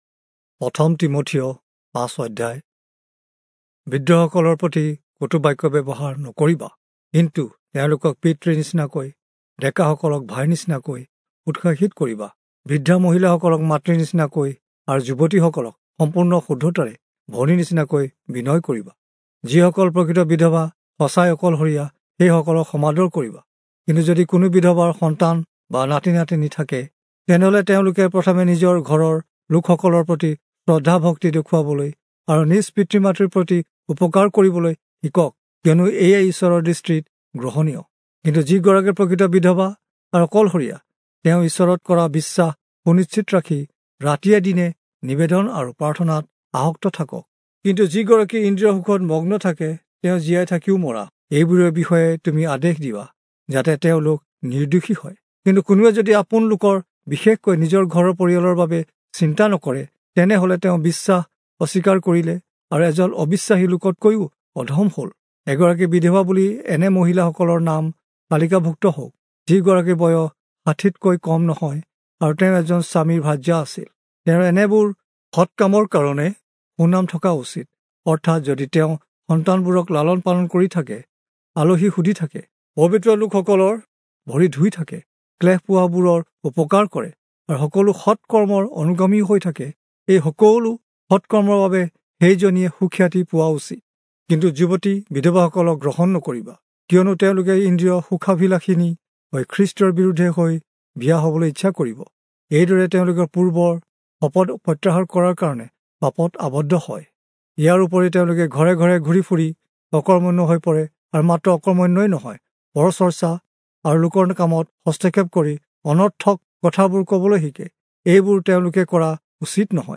Assamese Audio Bible - 1-Timothy 5 in Bnv bible version